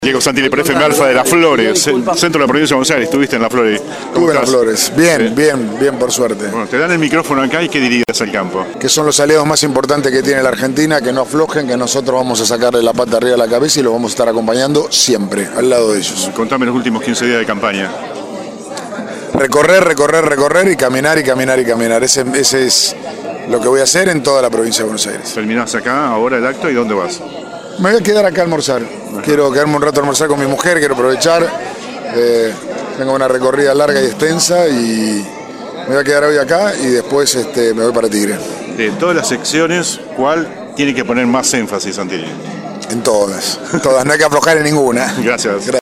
El precandidato a gobernador por Juntos por el Cambio -alineado a Horacio Rodríguez Larreta- también habló en exclusiva para FM Alpha desde Palermo.